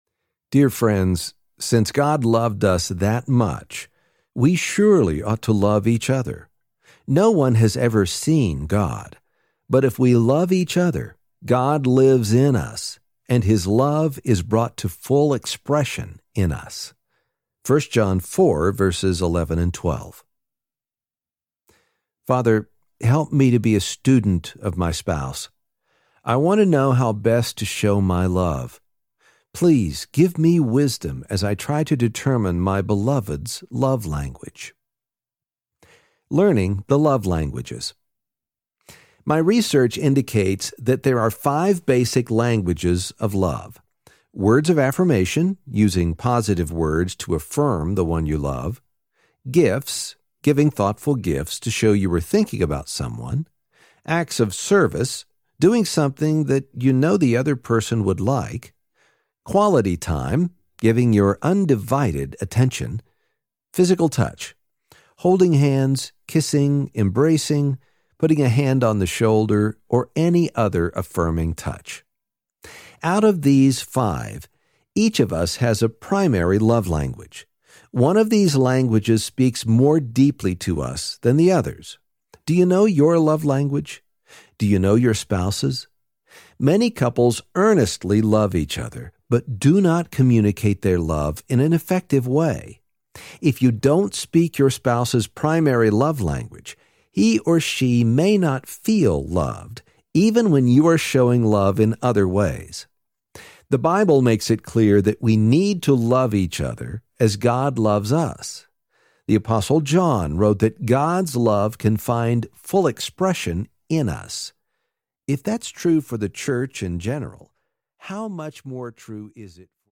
Love Language Minute for Couples Audiobook
4.0 Hrs. – Unabridged